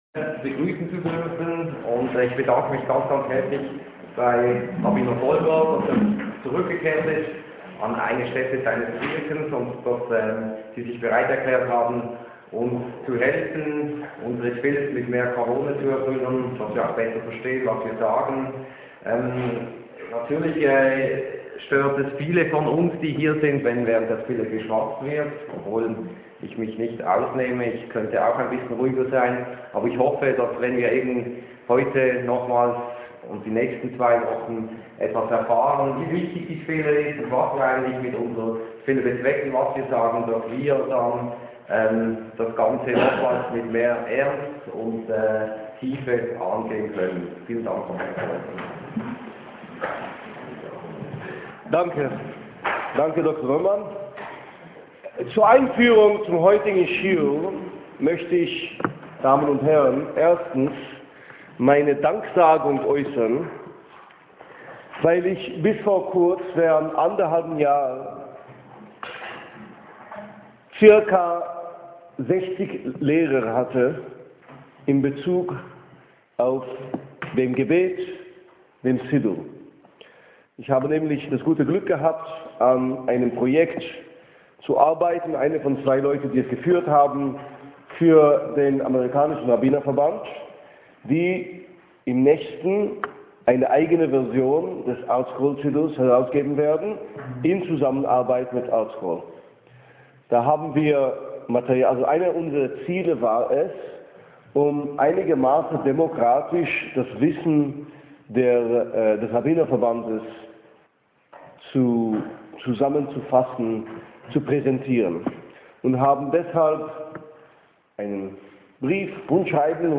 This list contains the older, mostly not yet properly tagged audio and video lectures.